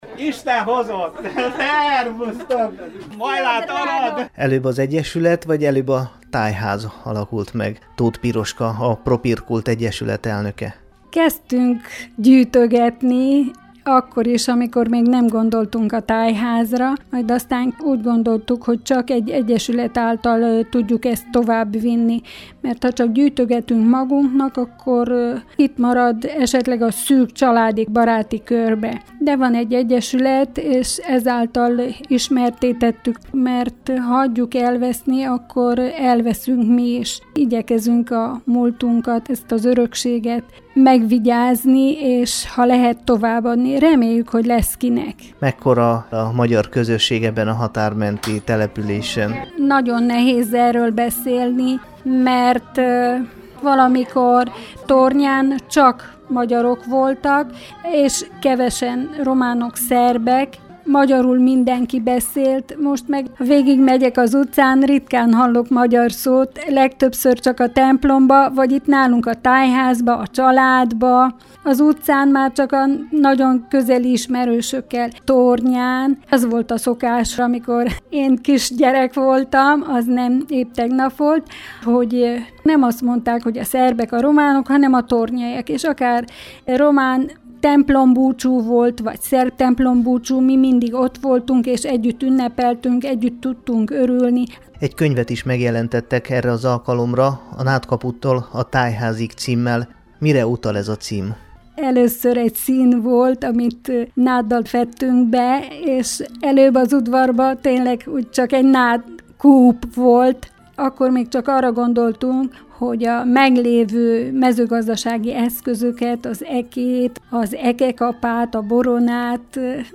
Hallgassa meg a Temesvári Rádió Magyar adása számára készített riportját